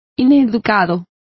Complete with pronunciation of the translation of uneducated.